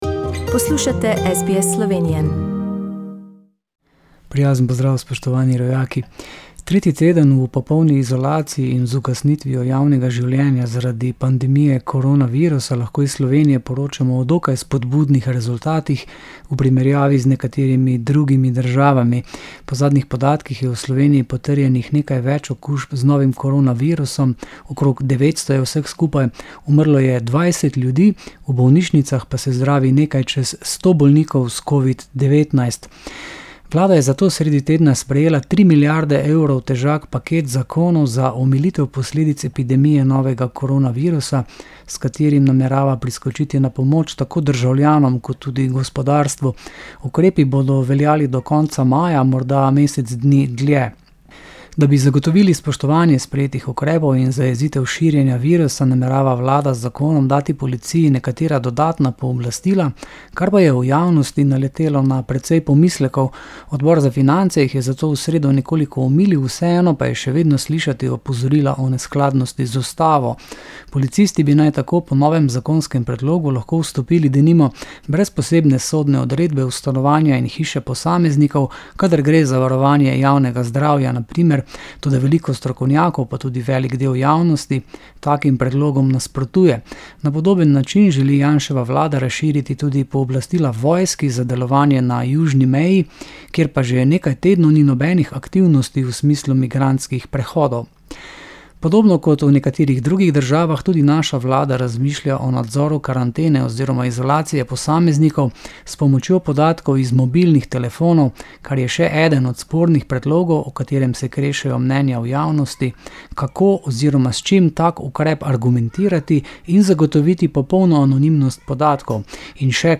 News from homeland 04.04.2020